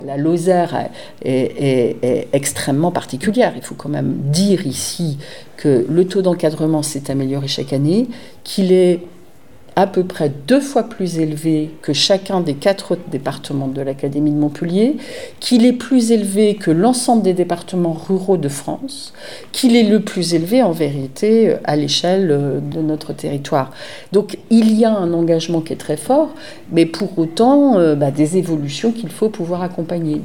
Mais le point presse de Sophie Béjean était également l’occasion de rappeler la situation particulière dont jouit la Lozère en matière du nombre de professeurs des écoles par élèves.